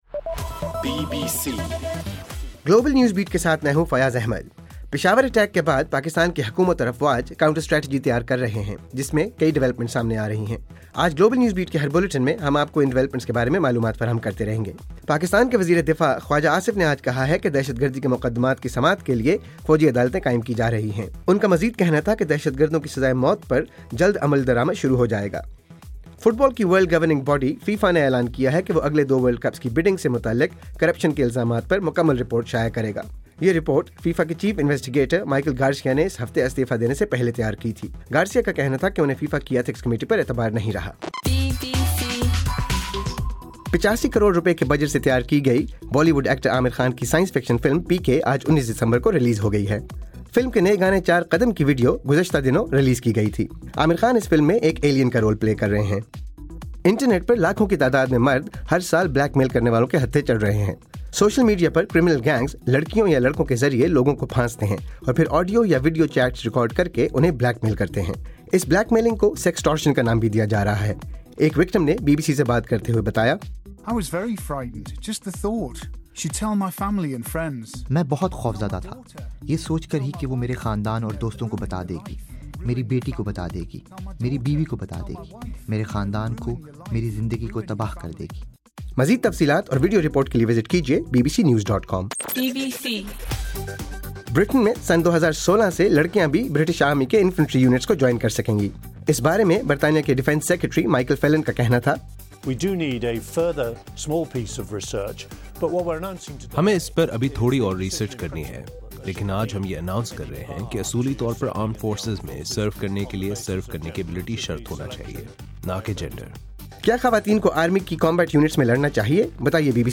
دسمبر 19: رات 9 بجے کا گلوبل نیوز بیٹ بُلیٹن